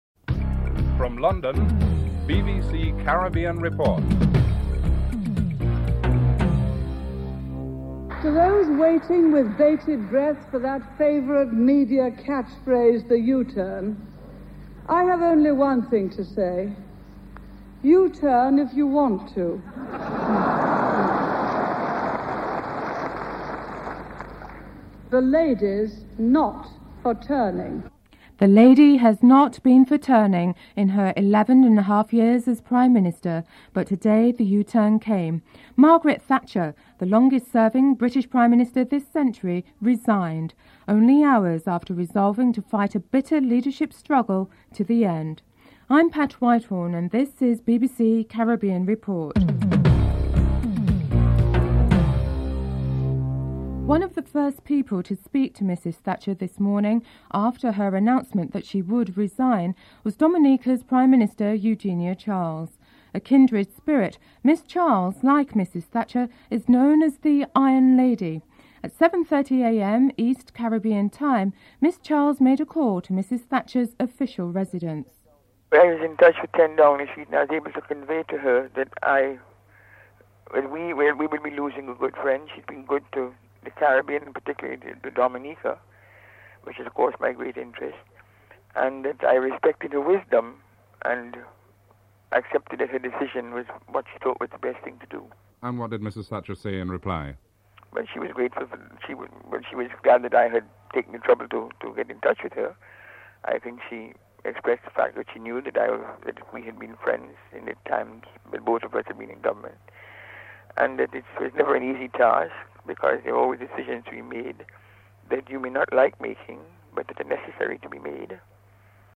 2. Eugenia Charles, Prime Minister of Dominica, comments on the events as one of the first persons to speak to Mrs. Thatcher after her resignation.
3. Guyanese-born British MP Bernie Grant states that Mrs. Thatcher’s resignation is long overdue and speculates on her successor.
7. The report ends with an excerpt of a speech by Margaret Thatcher in the Parliament two days earlier.